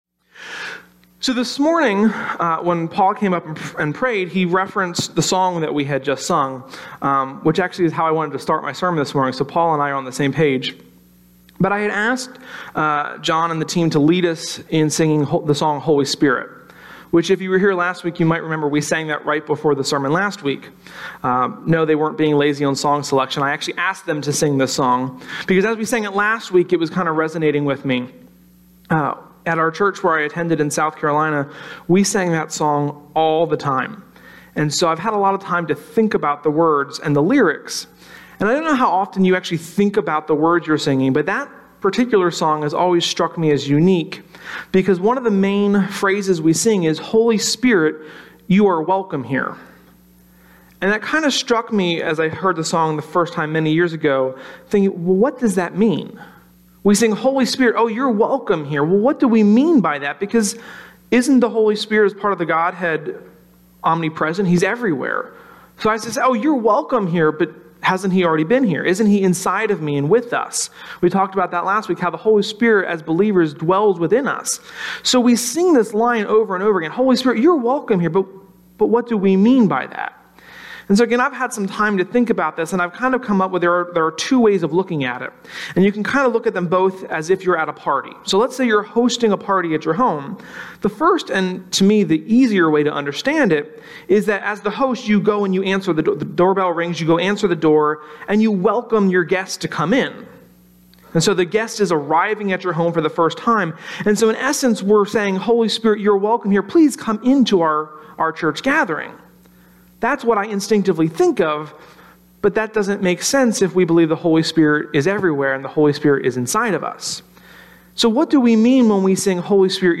Sermon-1.14.18.mp3